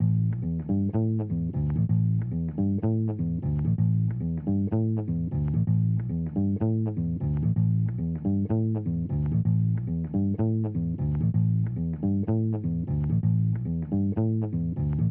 bass lead-remaster.wav